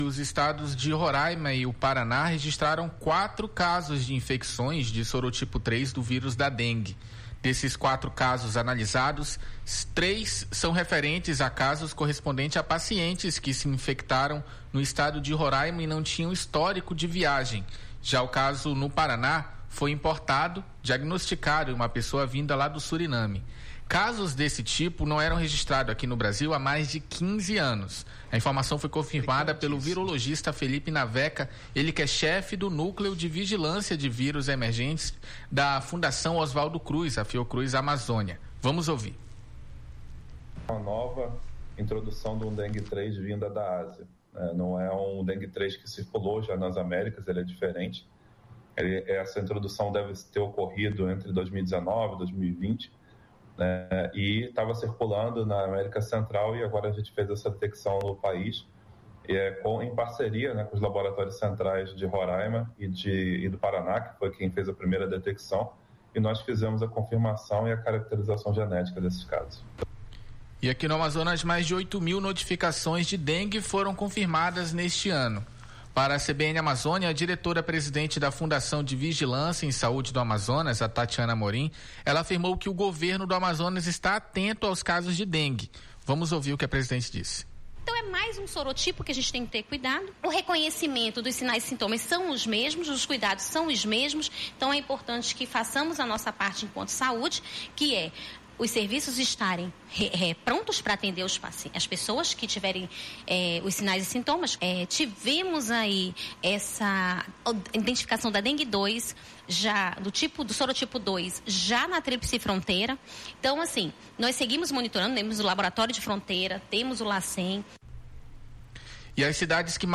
Casos desse tipo não eram registrados no Brasil há mais de 15 anos; em entrevista à CBN Amazônia, a diretora presidente da FVS-RCP, Tatyana Amorim, afirmou que o Governo do Amazonas está atento aos casos de dengue